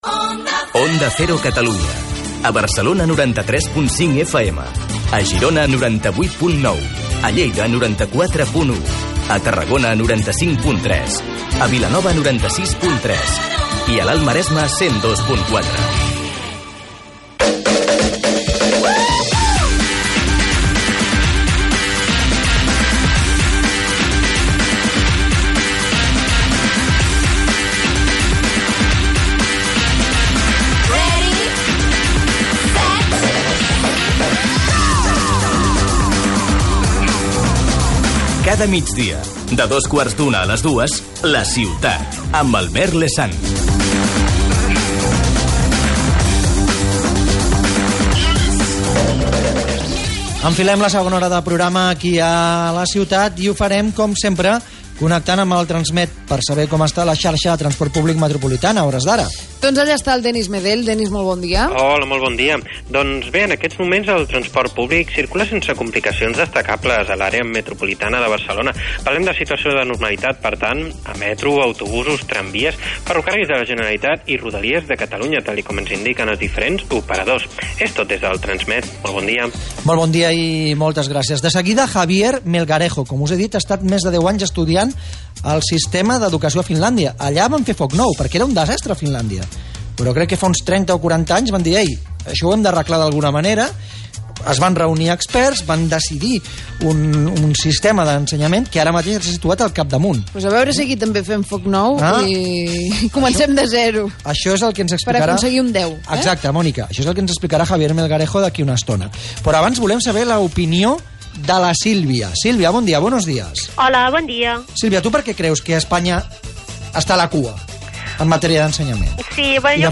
Radio: Podcast programa «La Ciutat» de OndaCero Catalunya. Nuevos modelos de pareja. 20 Octubre 2014